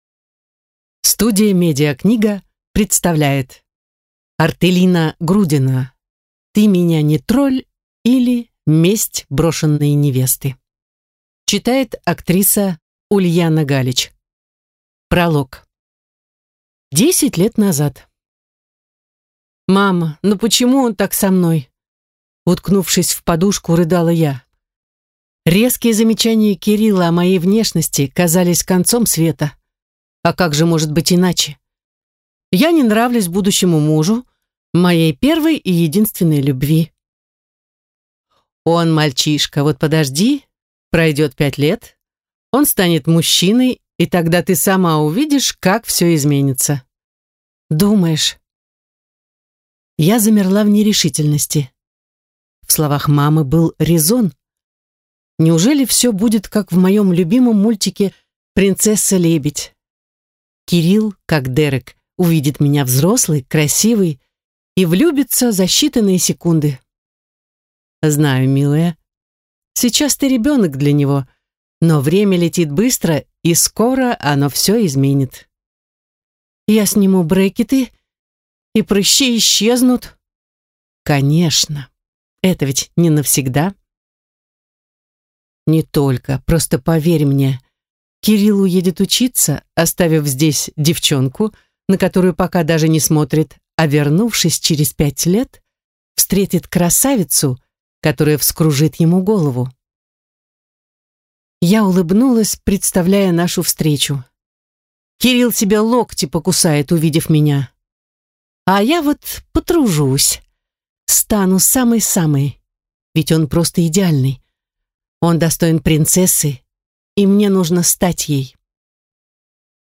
Аудиокнига Месть брошенной невесты | Библиотека аудиокниг